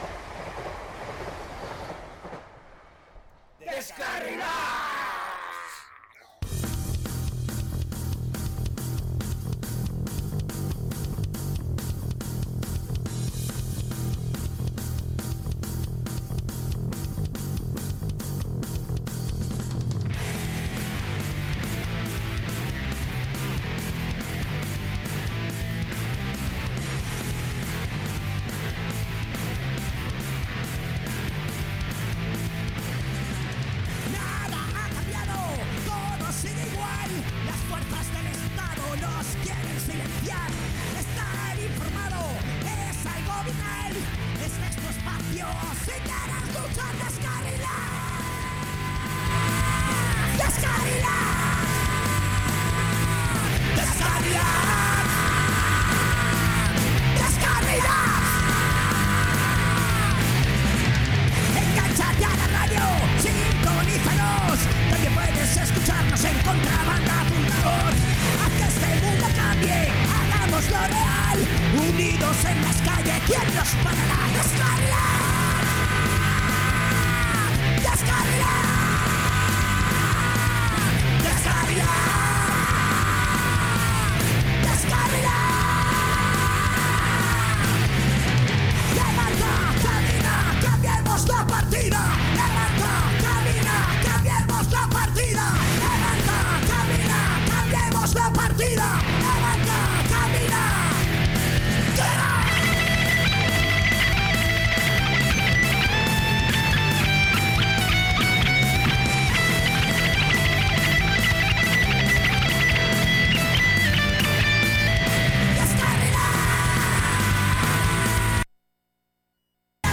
En el 98 programa de deskarrilats un poco problemático por cambios tecnicos en la emisora Radio Contrabanda en esta ocasión hablamos del grupo:”THE JAM”
Durante el programa ponemos diferentes canciones de ellos mientras repasamos su trayectoria